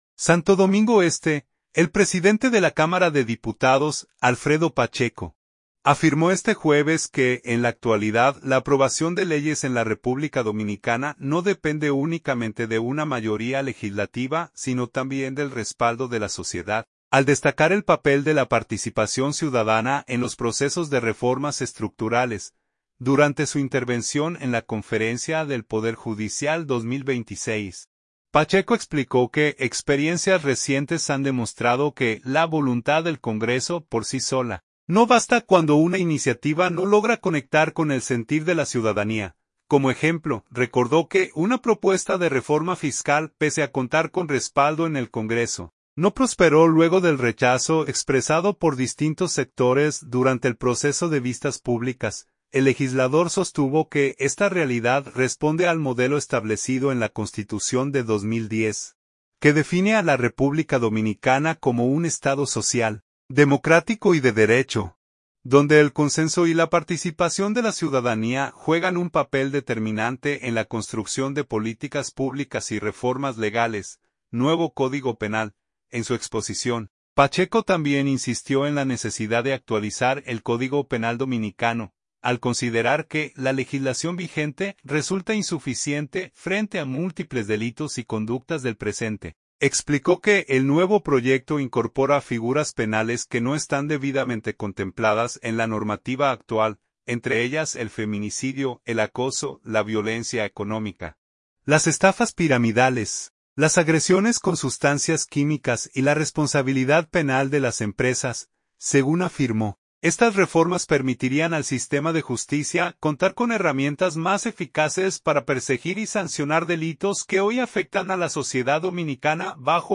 Las declaraciones fueron ofrecidas durante la apertura de la Conferencia del Poder Judicial 2026, celebrada en la Ciudad Judicial de Santo Domingo Este, con la participación del presidente de la República, Luis Abinader.